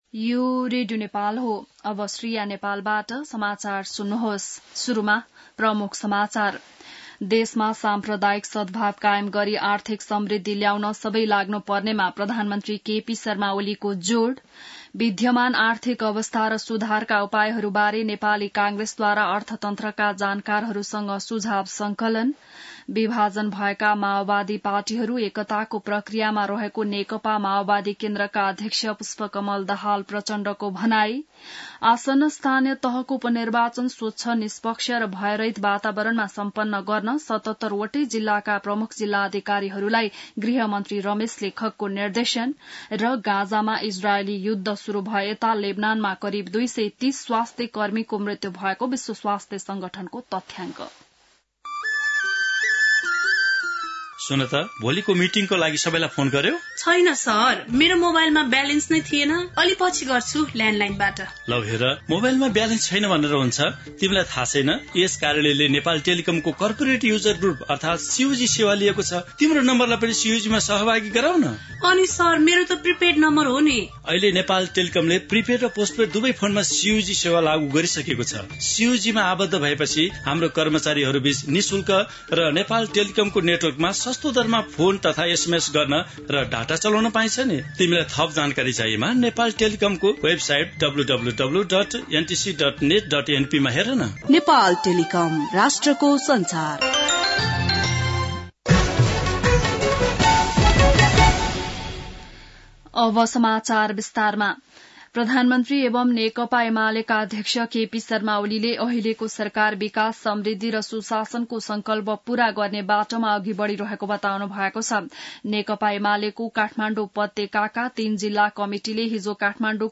बिहान ७ बजेको नेपाली समाचार : ९ मंसिर , २०८१